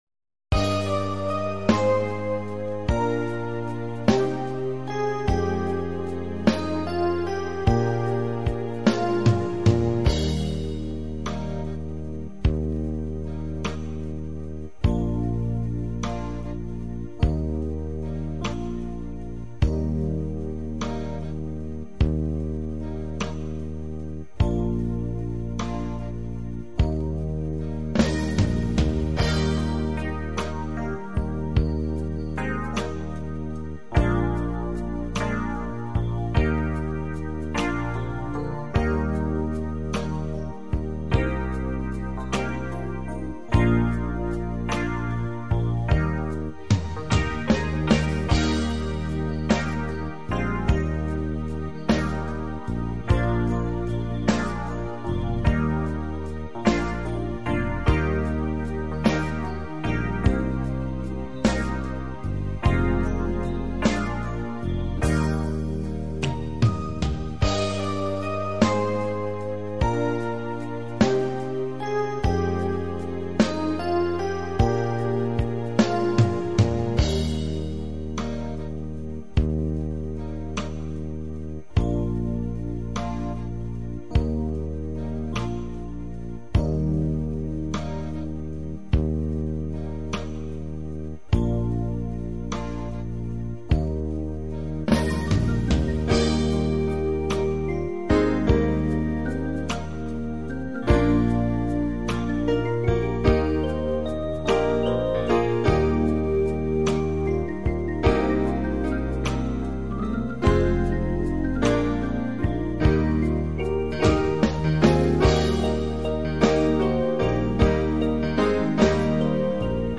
Playbacks-KARAOKE Zobrazení